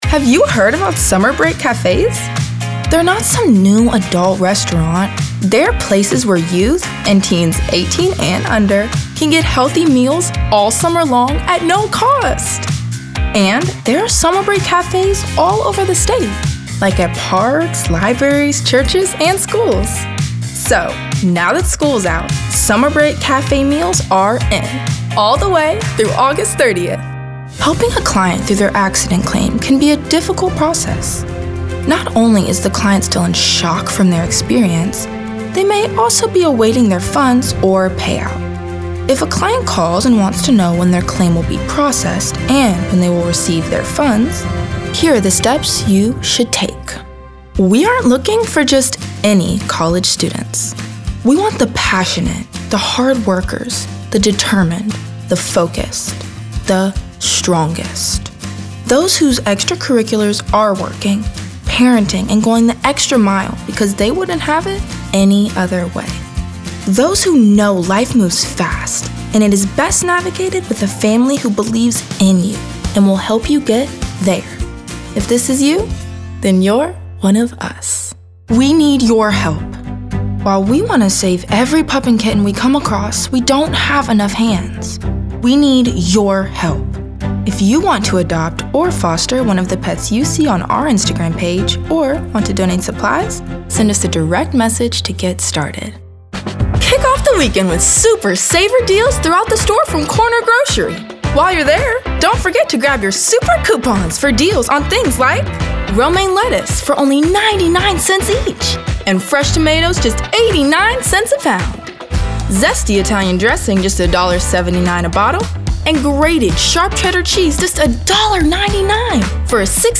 announcer, anti-announcer, attitude, caring, confessional, confident, conversational, cool, friendly, genuine, girl-next-door, high-energy, informative, inspirational, motivational, perky, retail, soft-spoken, sweet, thoughtful, upbeat, warm, young adult